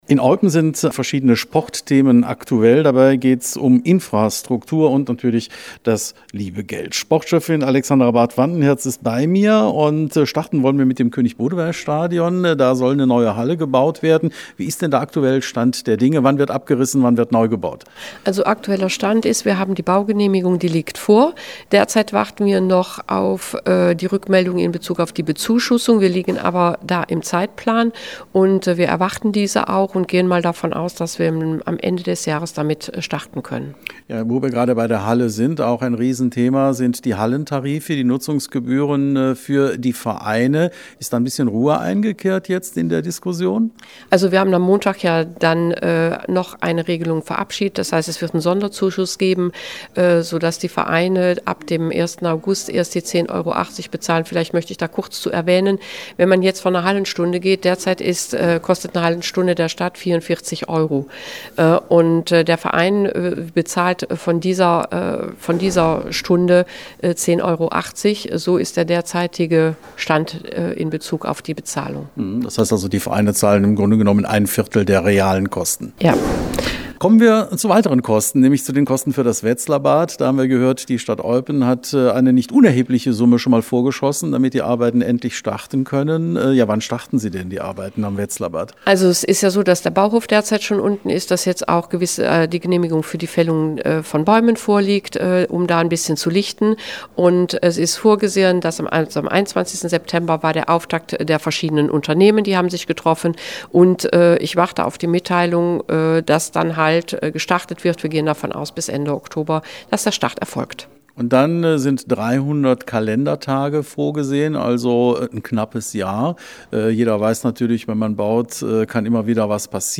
sprach mit Sportschöffin Alexandra Barth-Vandenhirtz.